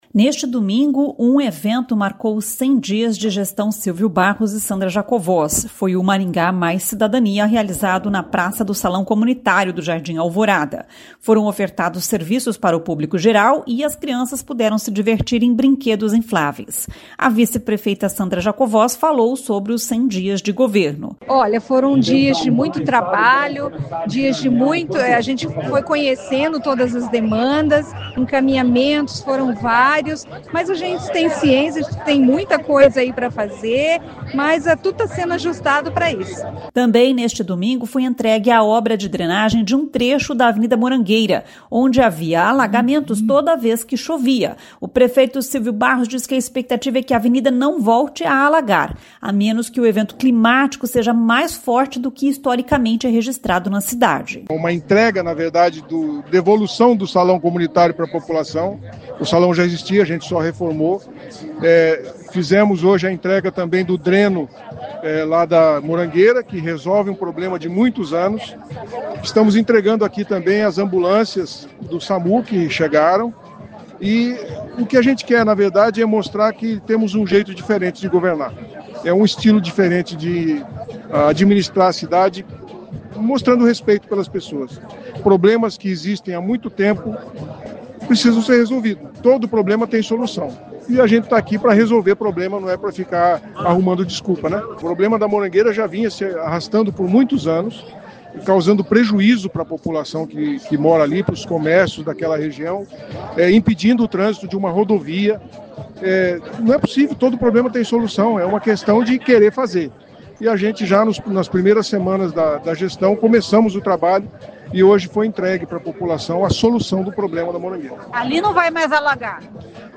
A vice-prefeita Sandra Jacovós falou sobre os 100 dias de governo.
O prefeito Silvio Barros diz que a expectativa é que a avenida não volte a alagar, a menos que o evento climático seja mais forte do que historicamente é registrado na cidade.
E ainda durante o Maringá + Cidadania foram entregues cinco novas ambulâncias para o Samu, vindas do Ministério da Saúde, diz o deputado federal Ricardo Barros.